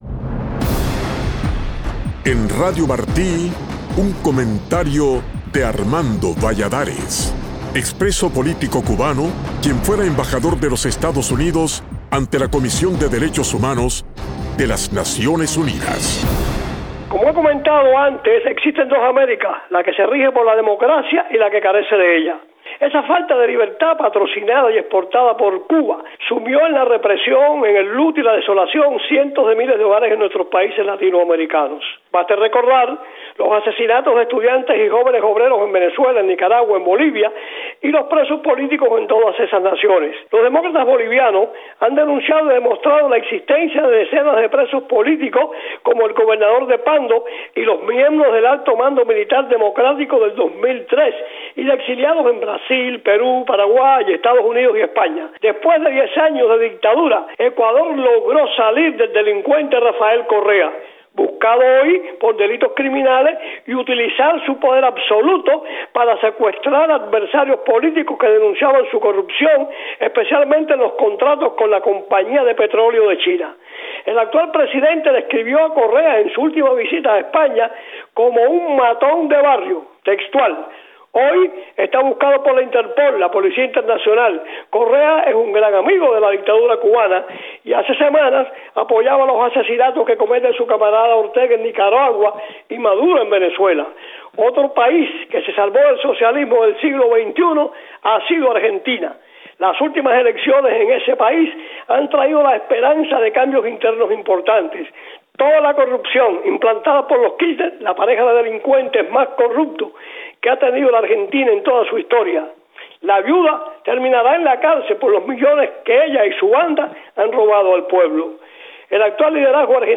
La falta de libertad patrocinada y exportada por el régimen cubano ha sumido en la represión, el luto y la desolación a cientos de miles de hogares en nuestros países latinoamericanos. El embajador Armando Valladares analiza en su comentario de hoy los casos de Ecuador, bajo Rafael Correa, y la Argentina que gobernaron los Kirchner.